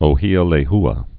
(ō-hēə lā-hə)